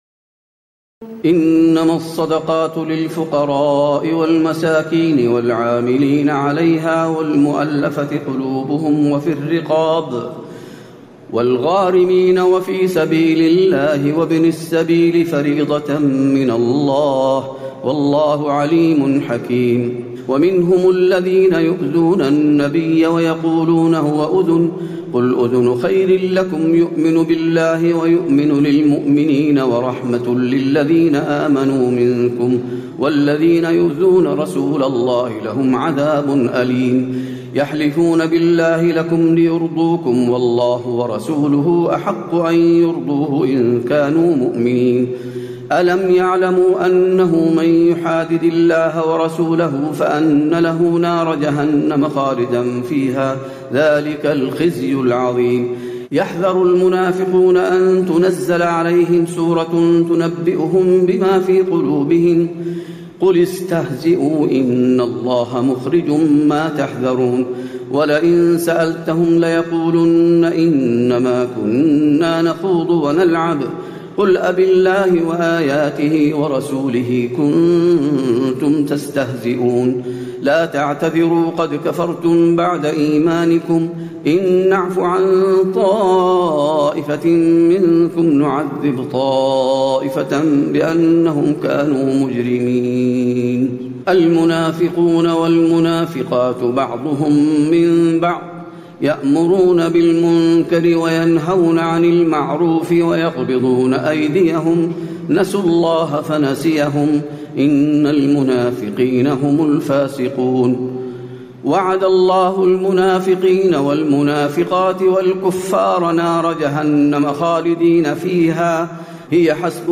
تراويح الليلة العاشرة رمضان 1436هـ من سورة التوبة (60-92) Taraweeh 10 st night Ramadan 1436H from Surah At-Tawba > تراويح الحرم النبوي عام 1436 🕌 > التراويح - تلاوات الحرمين